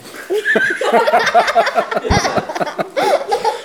ajout des sons enregistrés à l'afk ...
rire-foule_06.wav